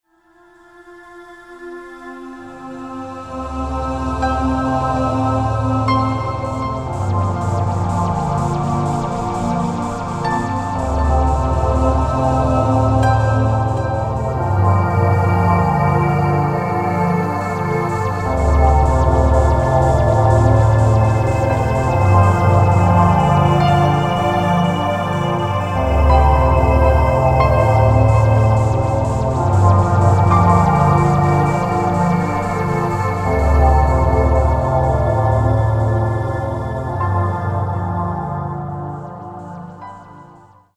クリアな音が僕達の五感を刺激します。浮遊感のファンタジックマジック！！！